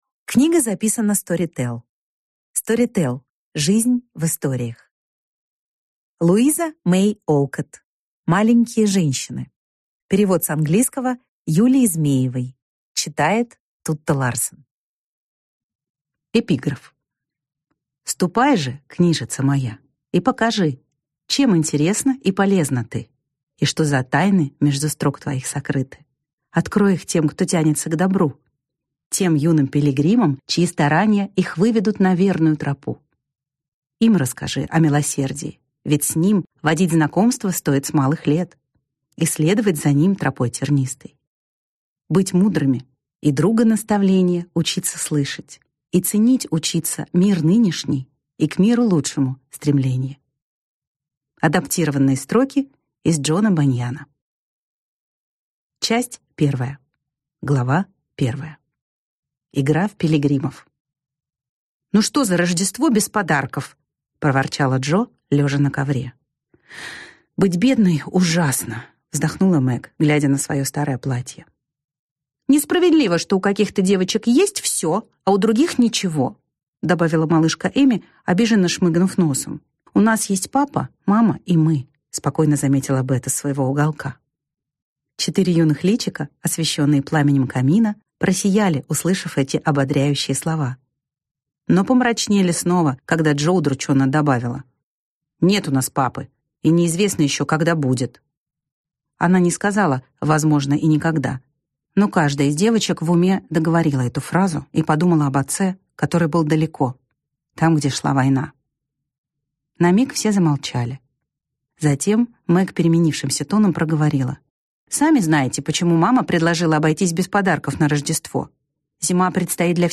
Аудиокнига Маленькие женщины | Библиотека аудиокниг